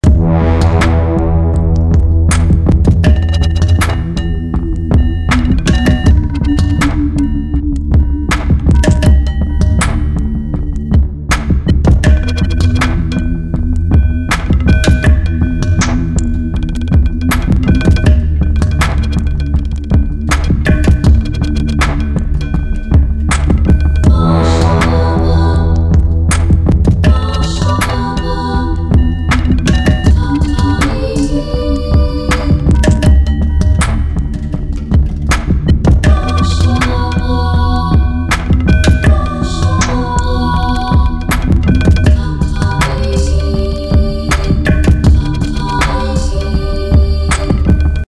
Zusammen mit den anderen Instrumenten: